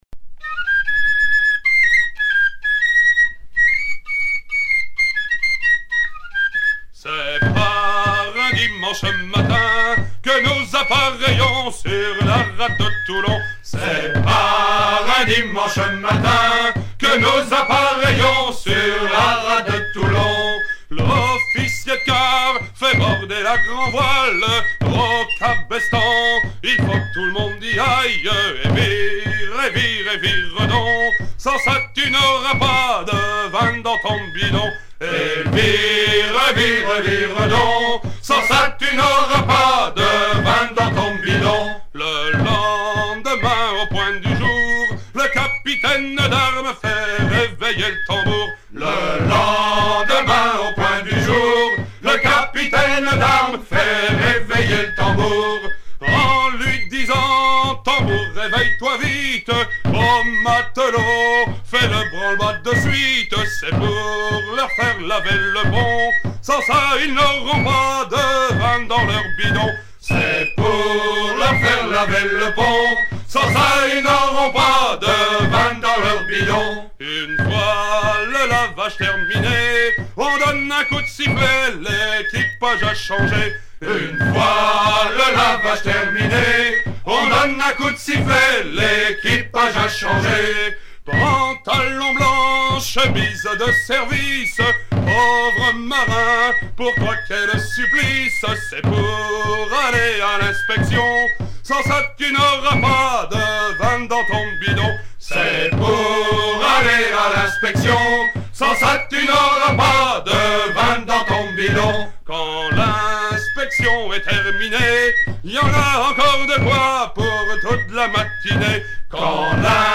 Version recueillie en 1974
gestuel : à virer au cabestan
Genre strophique